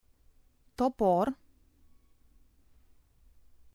topor